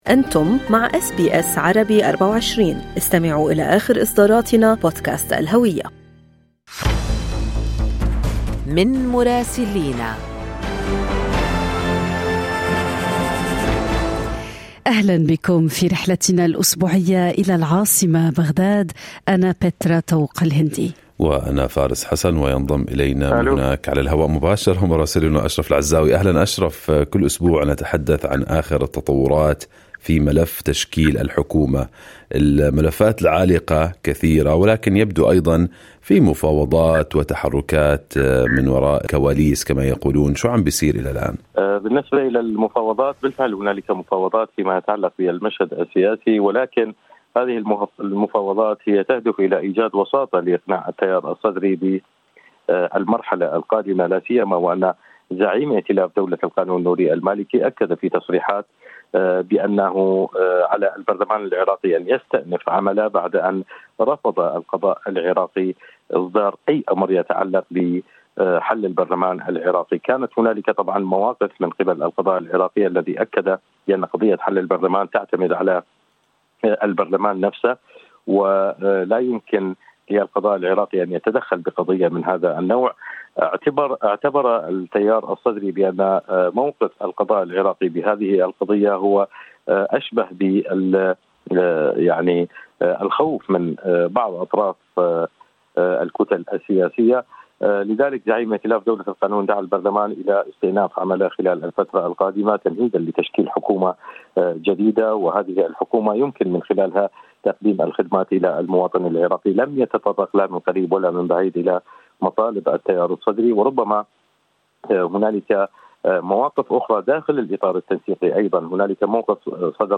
يمكنكم الاستماع إلى تقرير مراسلنا في بغداد بالضغط على التسجيل الصوتي أعلاه.